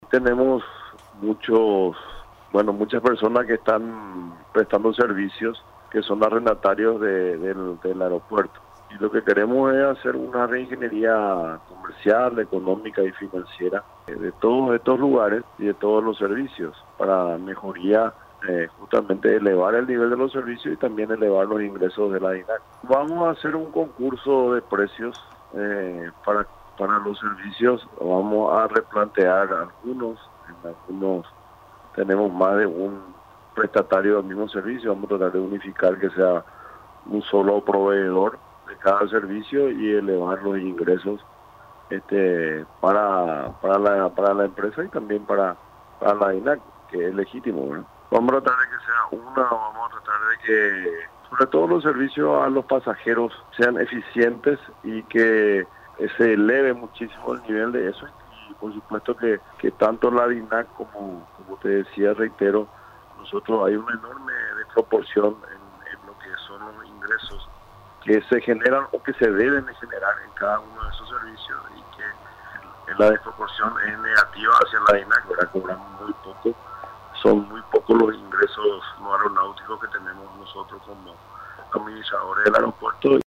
Todos los negocios que se encuentran en el aeropuerto internacional Silvio Pettirossi serán cerrados, manifestó el titular de la Dirección Nacional de Aeronáutica Civil (DINAC) Edgar Melgarejo.